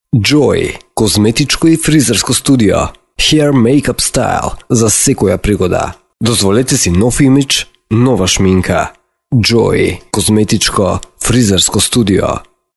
Deep Voice, Serious, Radio Sweepers, Jingles
Sprechprobe: Werbung (Muttersprache):